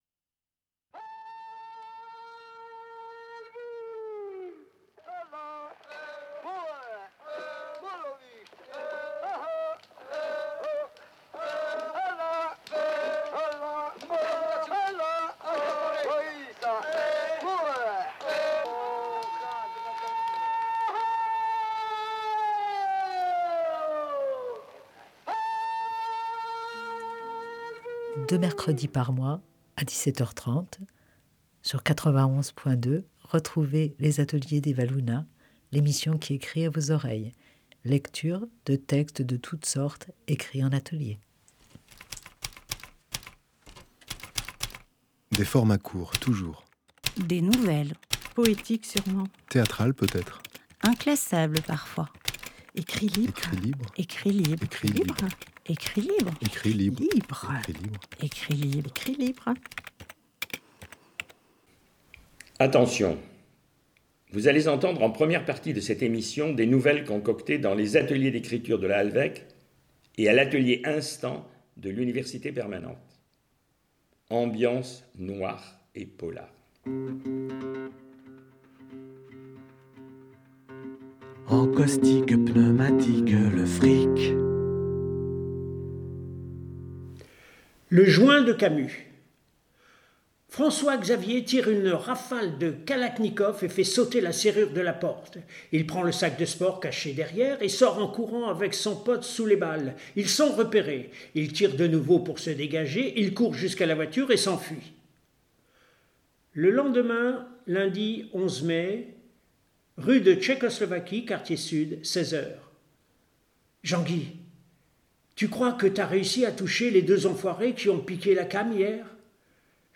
Lectures de nouvelles concoctées dans l’Atelier d’écriture de la Halvêque et dans l’atelier Instants de l’Université Permanente.